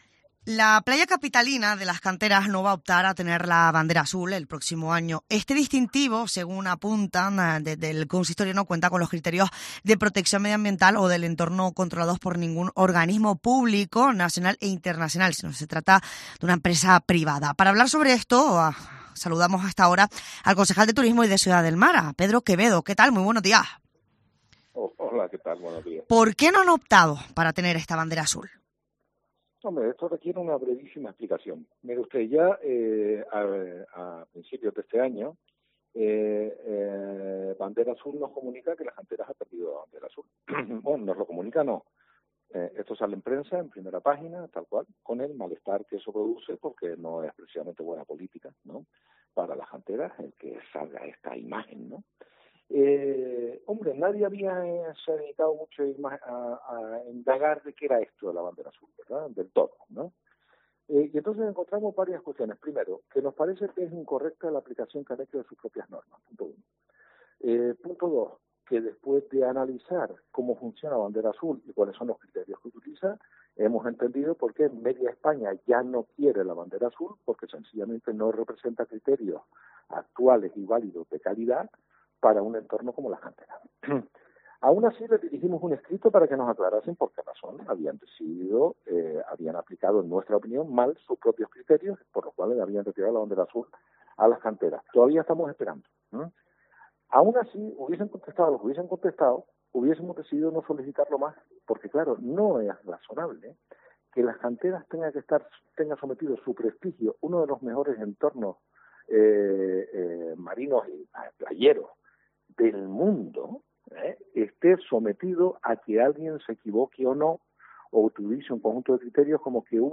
Pedro Quevedo, concejal de Turismo y Ciudad del Mar de Las Palmas de Gran Canaria
El concejal de Turismo y Ciudad del Mar de Las Palmas de Gran Canaria ha explicado en Herrera en Gran Canaria las razones que han llevado al consistorio capitalino a no solicitar este año la Bandera Azul para los arenales de la ciudad.